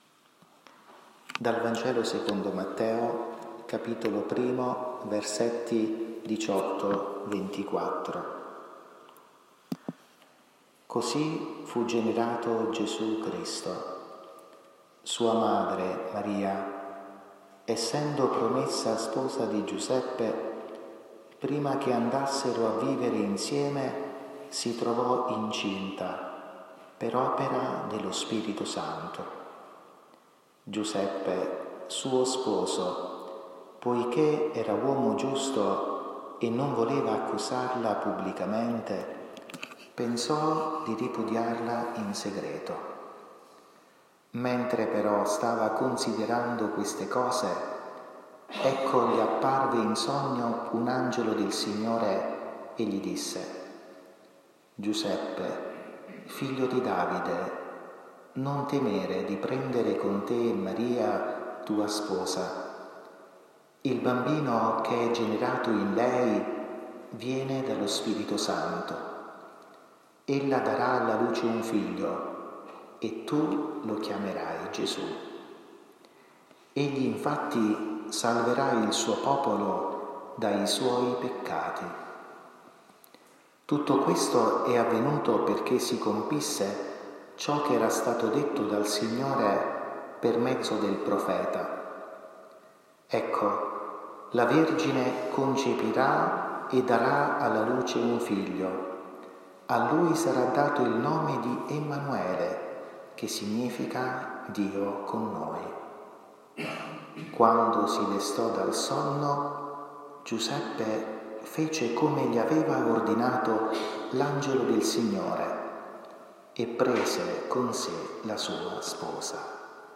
Catechesi mariana 23 maggio 2023.
Santuario Incoronata Montoro.